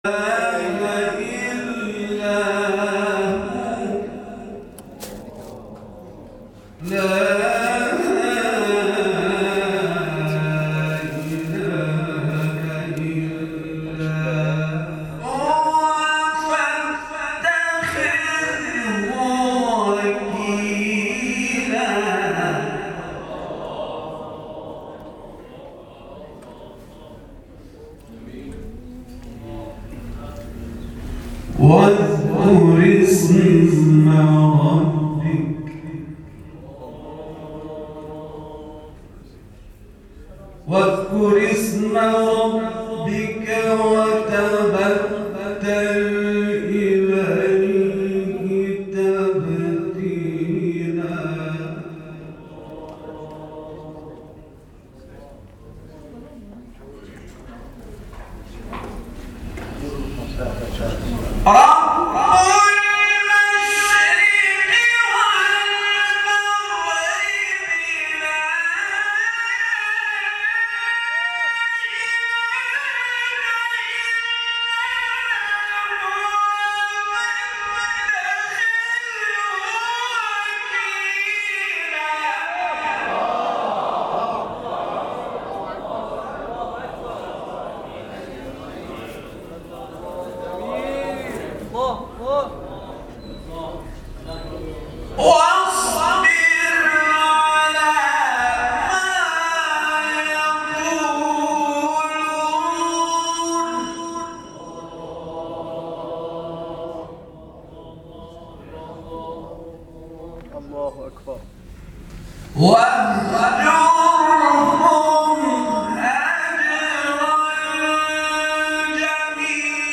گروه فعالیت‌های قرآنی: فرازهای صوتی از قاریان ممتاز کشور ارائه می‌شود.
در مقام حجاز